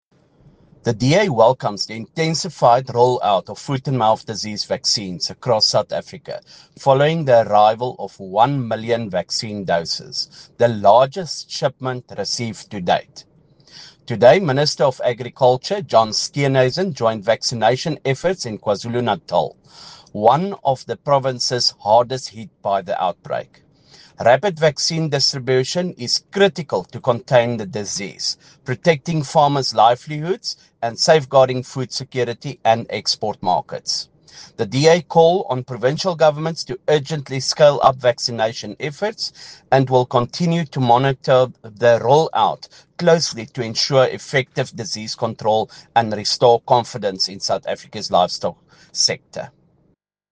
Afrikaans by Beyers Smit MP, DA Member on the Agriculture Portfolio Committee.
Beyers-Smit-MP_English.mp3